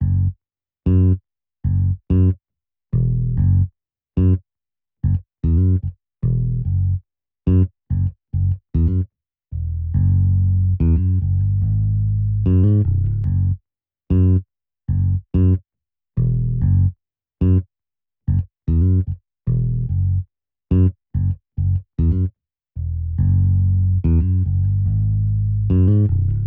02 bass A.wav